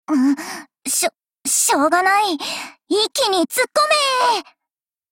贡献 ） 协议：Copyright，其他分类： 分类:碧蓝航线:星座语音 您不可以覆盖此文件。
Cv-10401_warcry.mp3